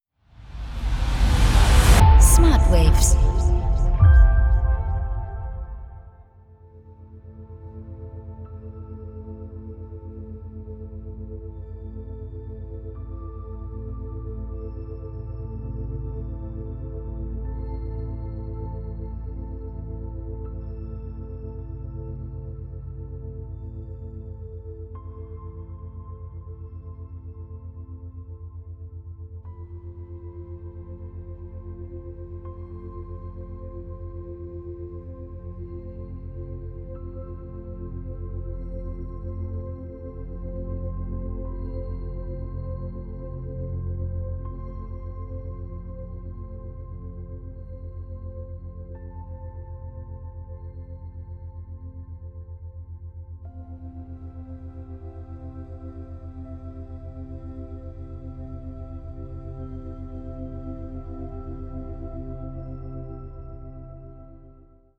• Methode: Binaurale Beats
• Frequenz: 7 Hertz